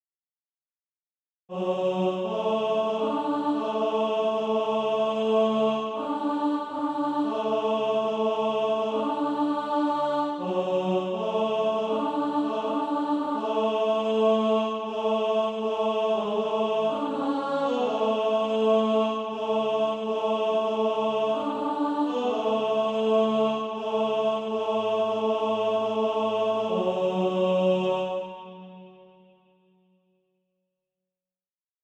(SATB) Author